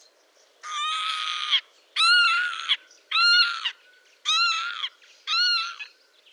Kiebitz Ruf
Wegen seines markanten Rufs „kiewit“, der ihm seinen Namen gab.
Kiebitz-Ruf-Voegel-in-Europa.wav